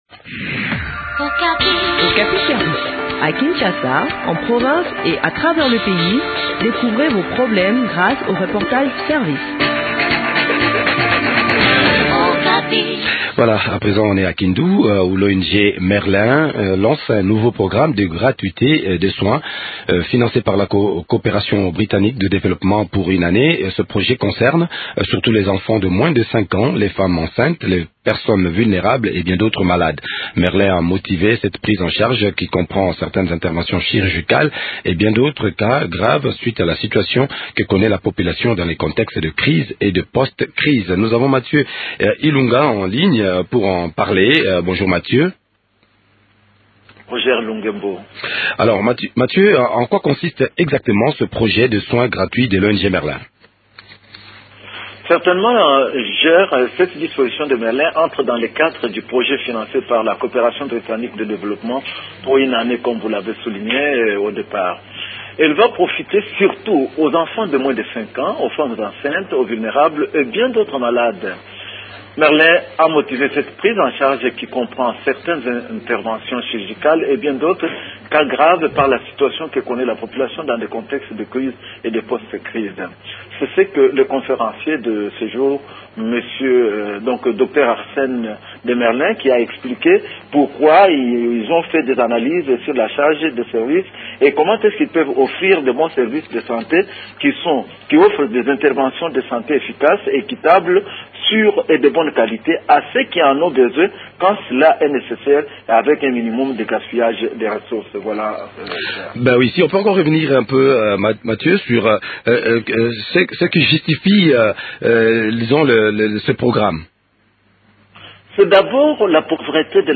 Le point sur le déroulement de cette campagne de soin de santé dans cet entretien